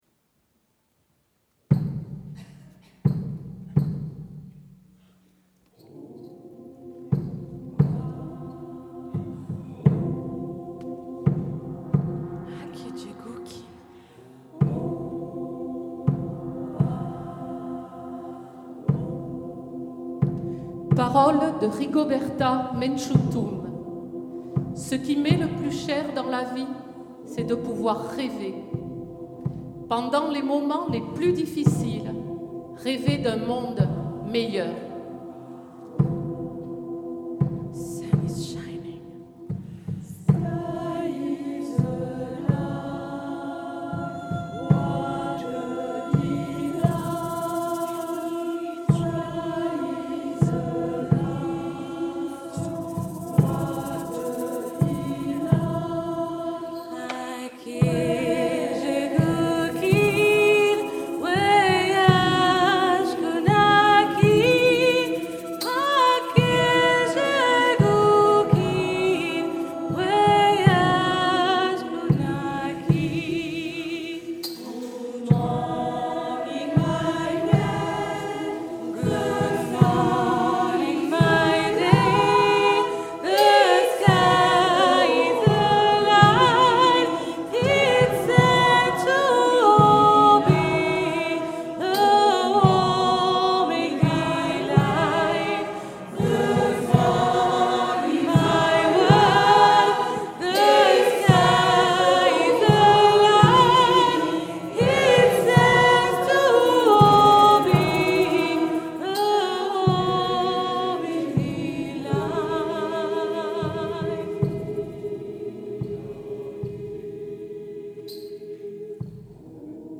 Activités de loisirs chant choral, yoga, qiqong, marche, danse, peinture, etc